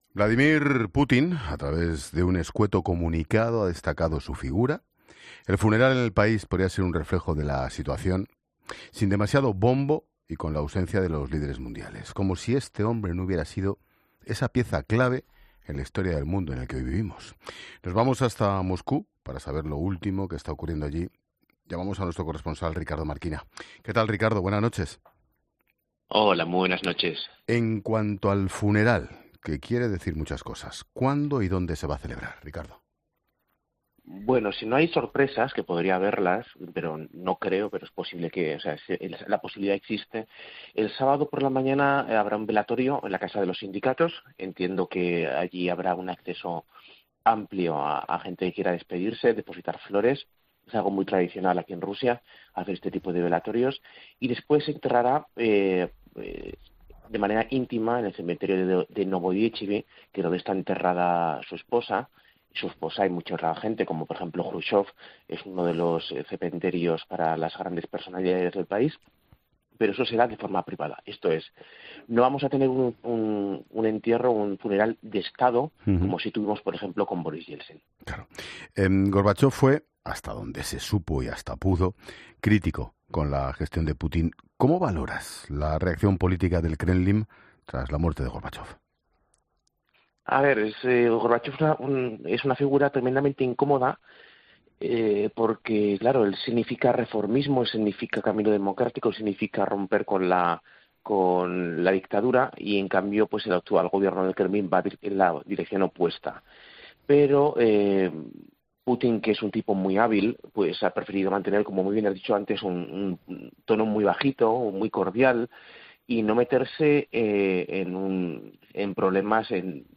Internacional